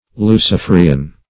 Lucifrian \Lu*cif"ri*an\, a.